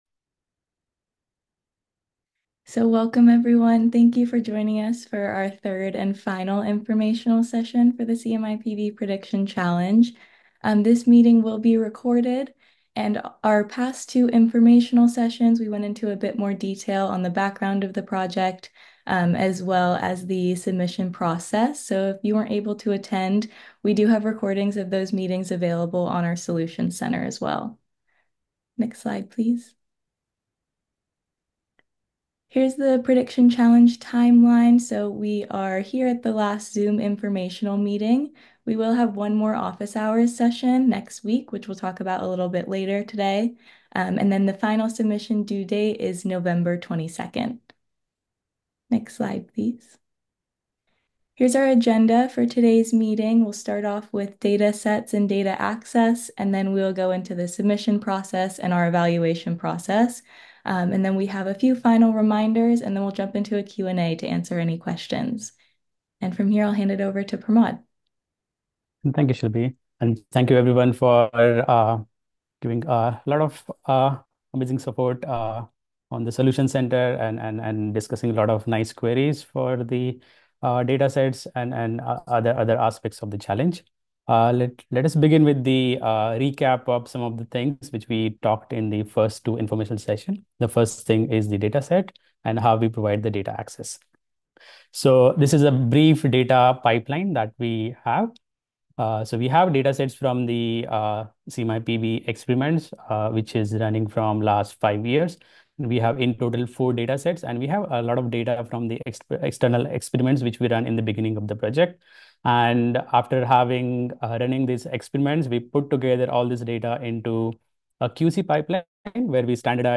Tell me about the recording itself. Please find the recording and slides from the 3rd Zoom Informational Session held on Friday, November 8th at 10am PT/1pm ET below.